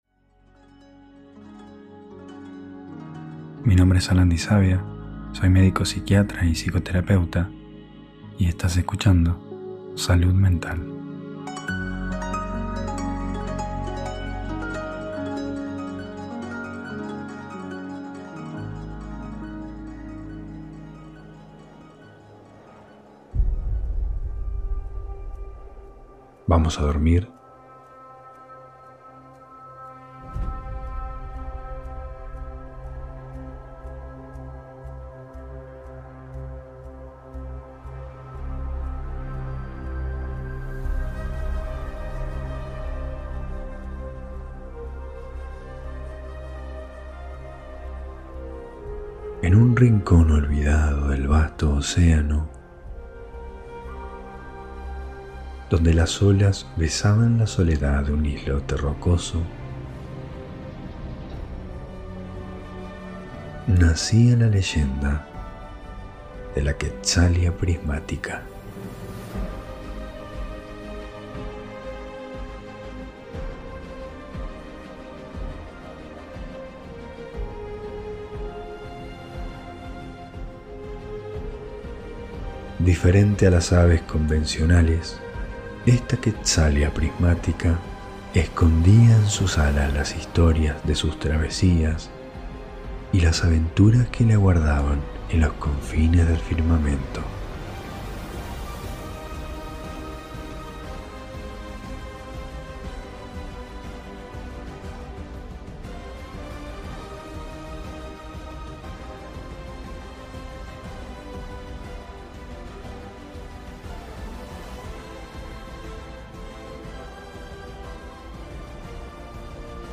Cuentos para dormir - El Vuelo de Quetzalia